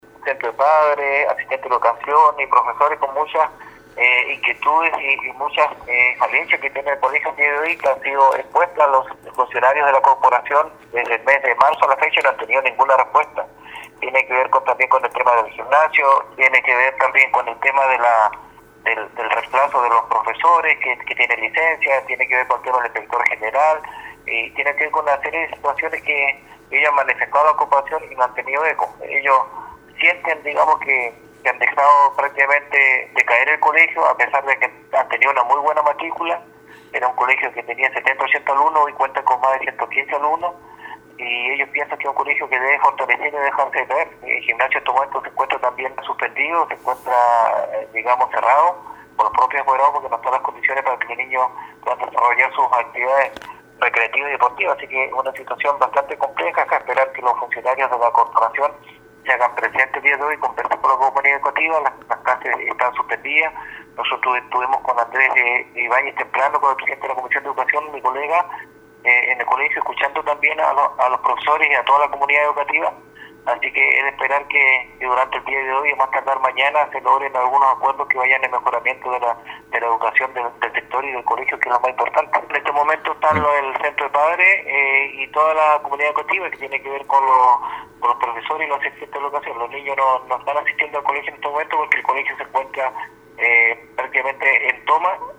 A su vez, el concejal Alex Muñoz, estableció que la comunidad escolar se encuentra con estas inquietudes, que deberían ser respondidas por la autoridad, porque se trata de un establecimiento con alta matrícula.
19-CONCEJAL-ALEX-MUNOZ.mp3